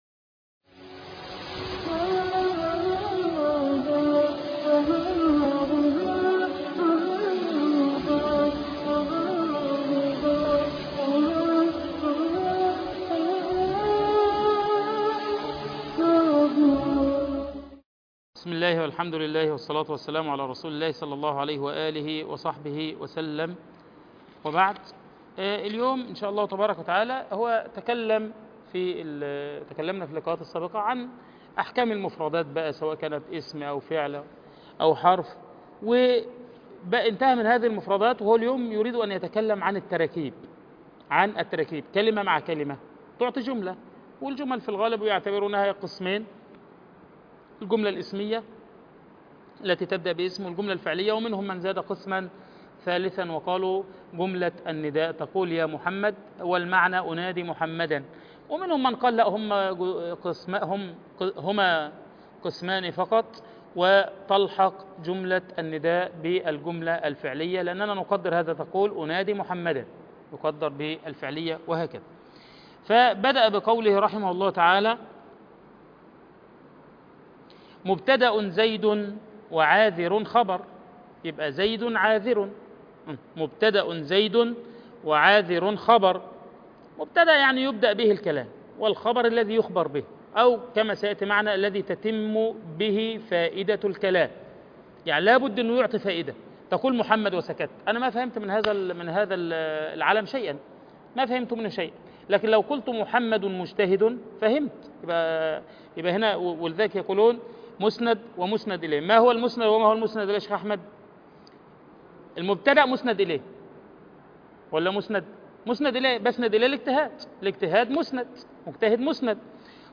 شرح متن ألفية ابن مالك - المحاضرة الرابعة عشر